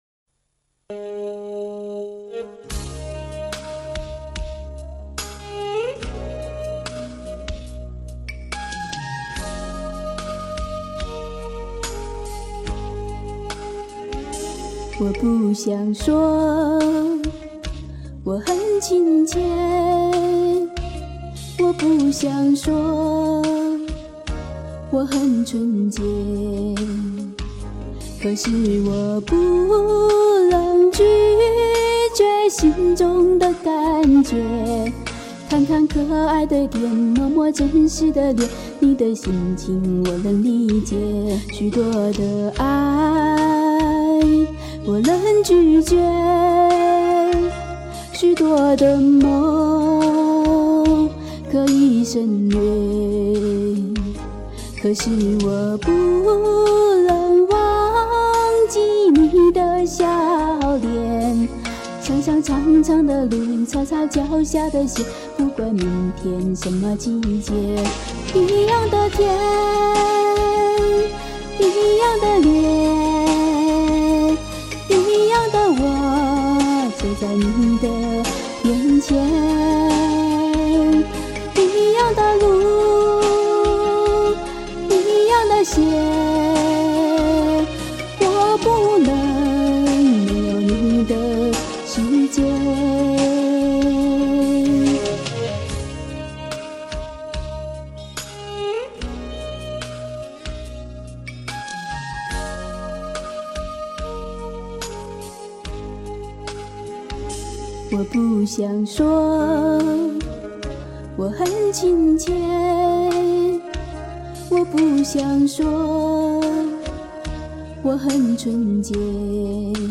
听得真舒服！